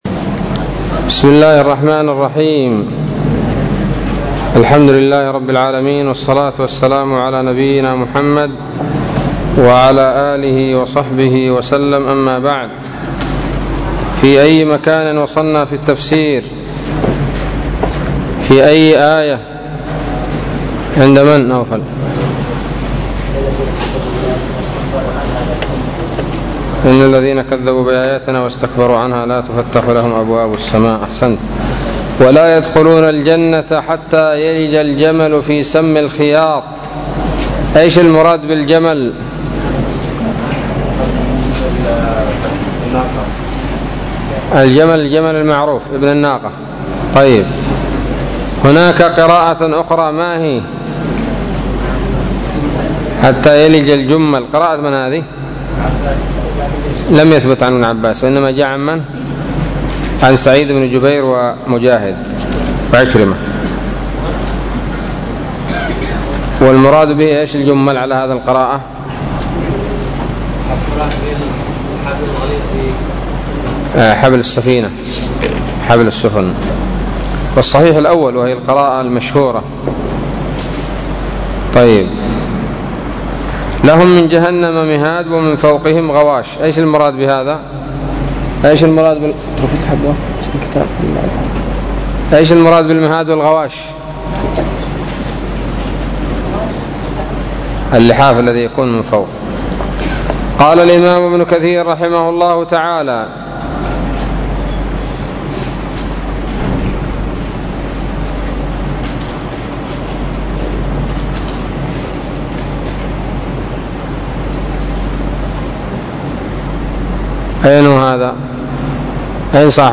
الدرس السابع عشر من سورة الأعراف من تفسير ابن كثير رحمه الله تعالى